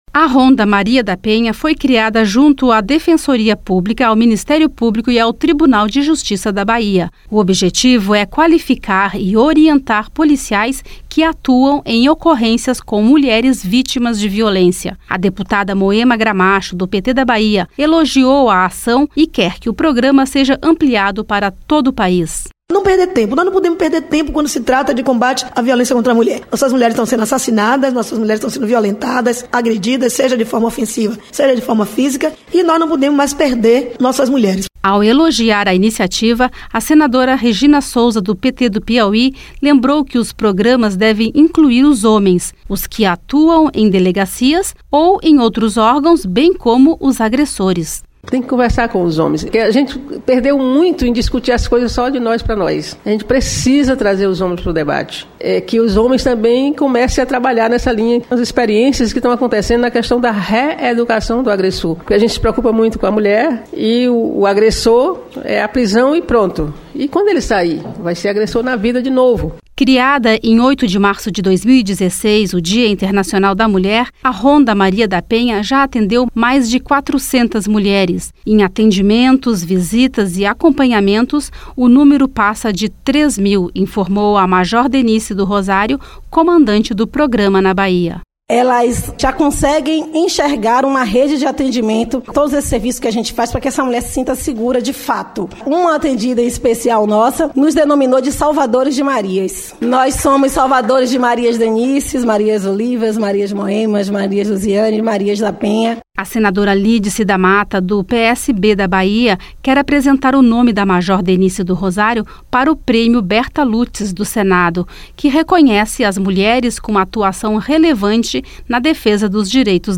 O objetivo é qualificar e orientar policiais que atuam em ocorrências com mulheres vítimas de violência. A deputada Moema Gramacho, do PT da Bahia elogiou a ação e quer que o programa seja ampliado para todo o país.